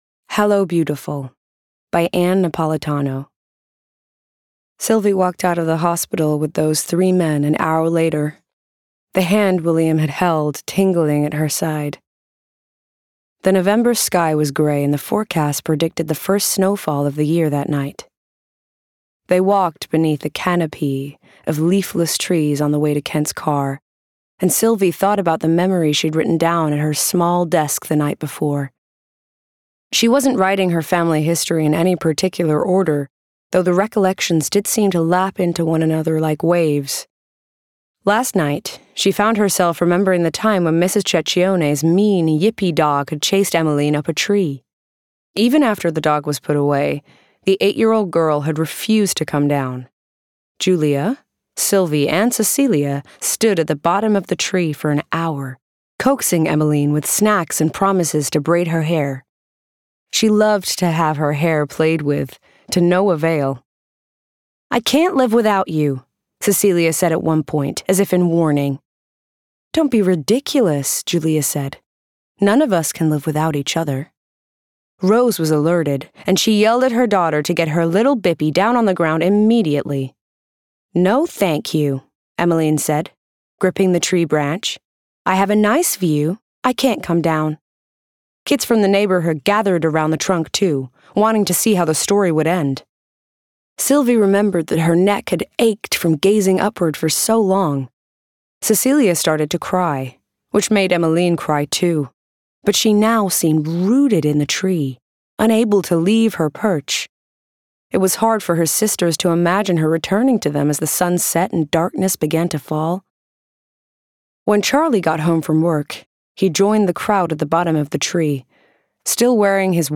***NEW ARTIST*** | 20s-30s | Transatlantic, Genuine & Charismatic